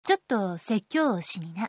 サンプルボイスは各キャラクターの下にあります